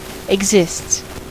Ääntäminen
Ääntäminen US Tuntematon aksentti: IPA : /ɪɡˈzɪsts/ Haettu sana löytyi näillä lähdekielillä: englanti Exists on sanan exist yksikön kolmannen persoonan indikatiivin preesens.